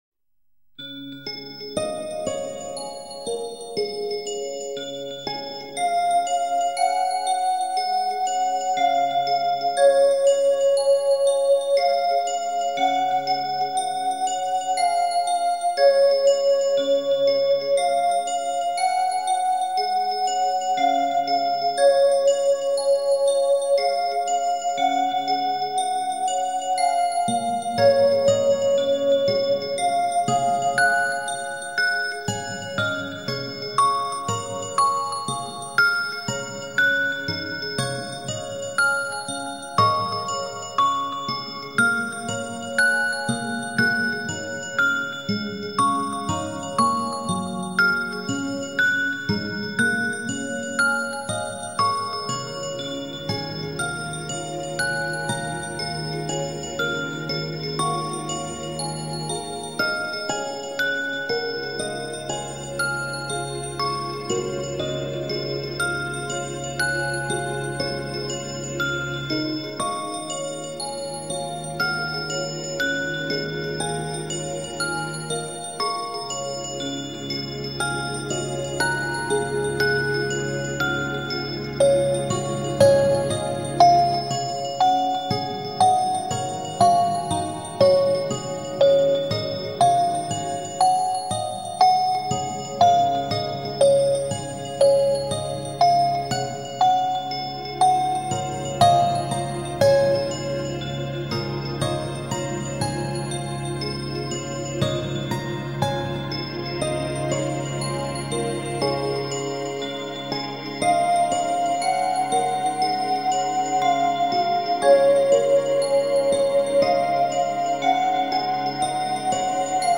🎶 Детские песни / Музыка детям 🎵 / Музыка для новорожденных